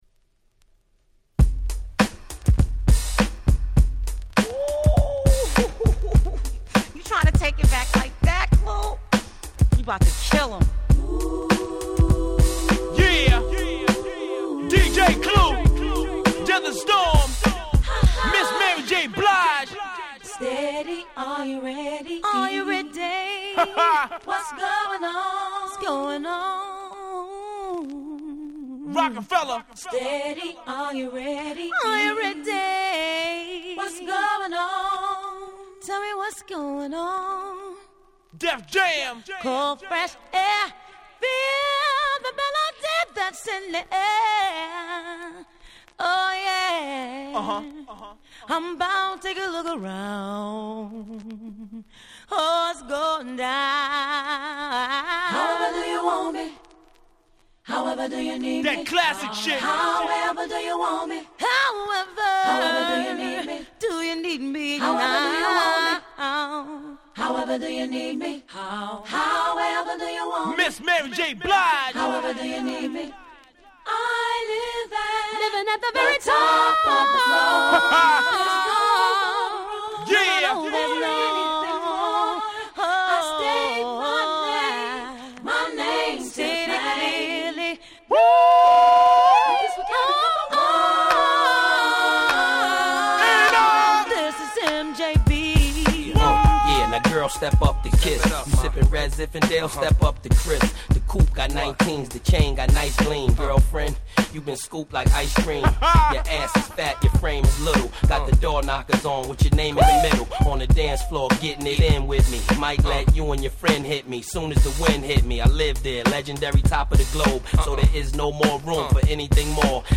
01' Super Hit R&B/Hip Hop !!
インピーチ使いのシンプルな作りで非常にナイスです！